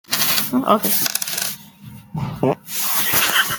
farty man Meme Sound Effect
farty man.mp3